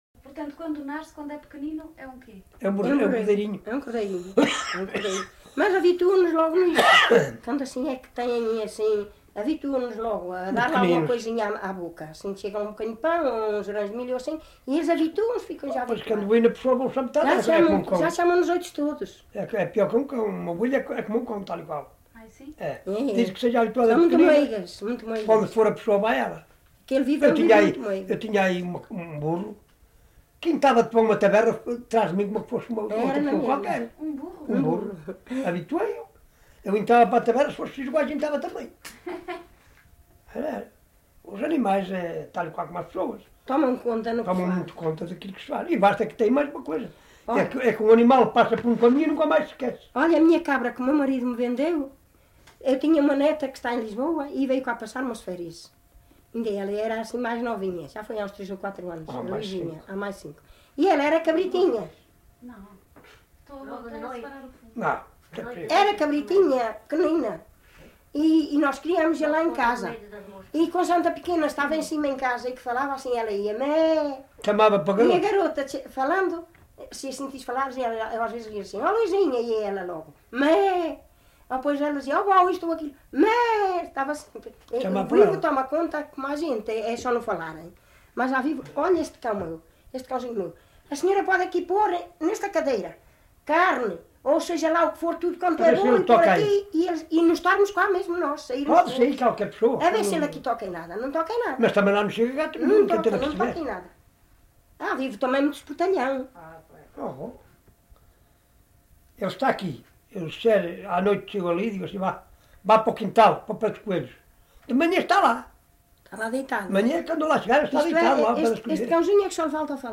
LocalidadeGranjal (Sernancelhe, Viseu)